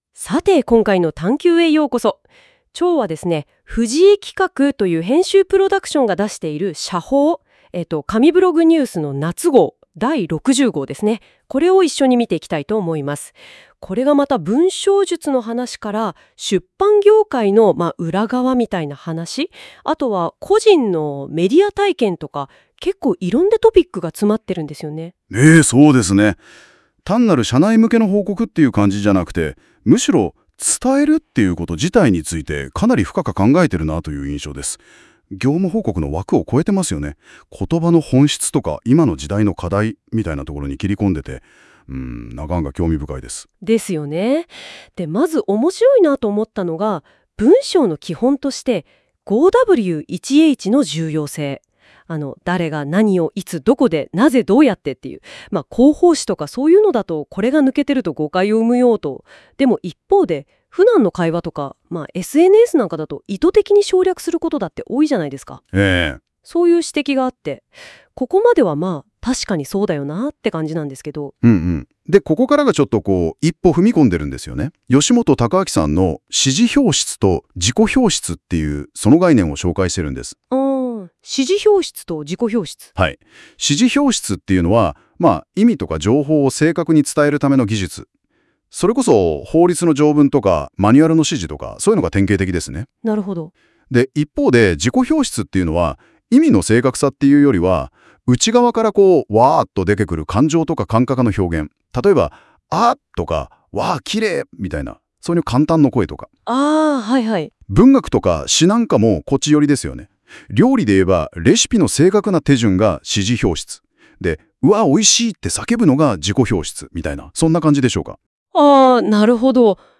なお、今回から実験的にAIによる社報『紙ブログ』紙面内容の音声解説アップしました。男女二人の対話形式で紹介しています。